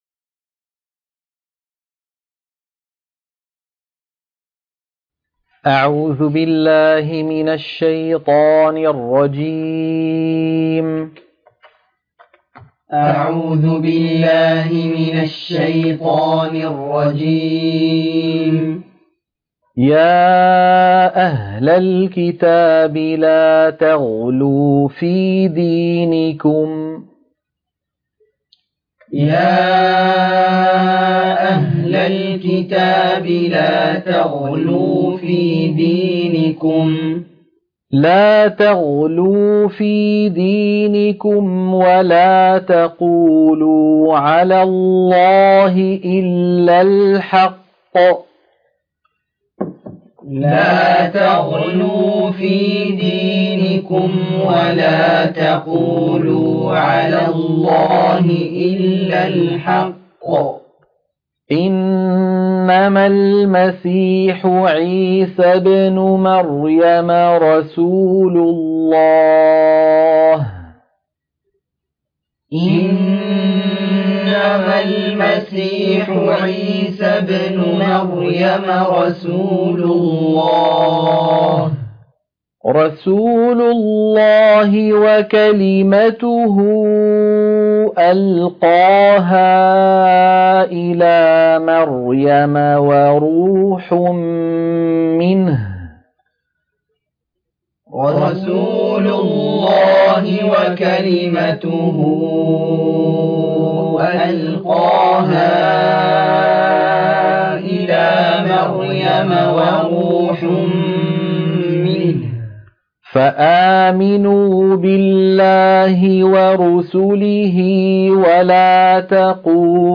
تلقين سورة النساء - الصفحة 105 التلاوة المنهجية - الشيخ أيمن سويد